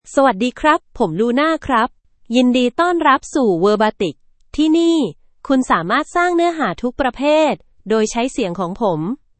FemaleThai (Thailand)
Luna — Female Thai AI voice
Luna is a female AI voice for Thai (Thailand).
Voice sample
Listen to Luna's female Thai voice.
Luna delivers clear pronunciation with authentic Thailand Thai intonation, making your content sound professionally produced.